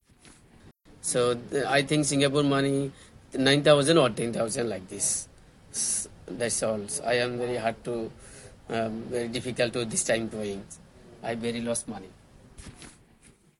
Here are some extracts from the debriefing interview.